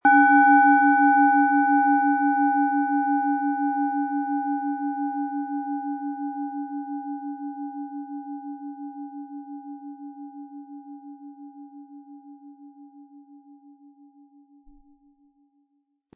Planetenton 1
Ein die Schale gut klingend lassender Schlegel liegt kostenfrei bei, er lässt die Planetenklangschale Mars harmonisch und angenehm ertönen.